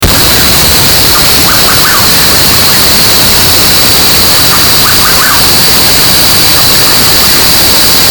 Rufous Nightjar (Antrostomus rufus)
Life Stage: Adult
Country: Argentina
Province / Department: Salta
Condition: Wild
Certainty: Recorded vocal